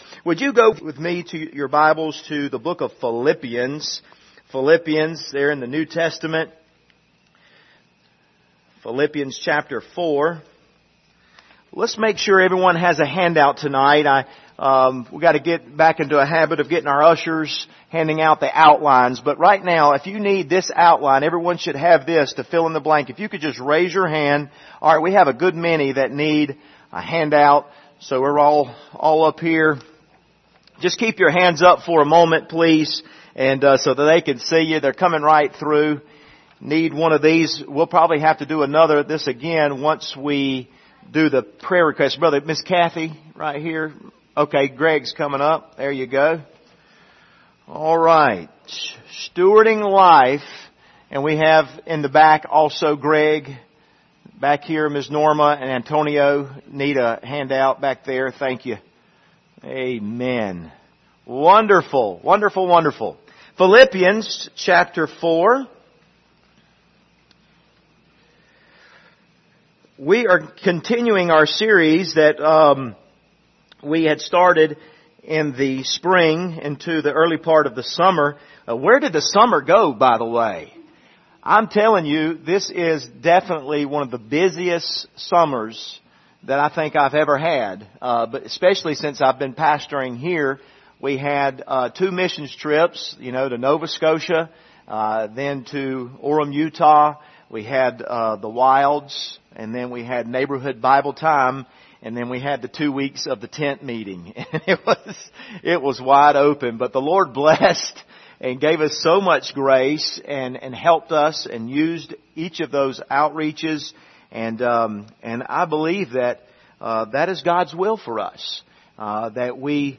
Passage: Philippians 4:4-8 Service Type: Wednesday Evening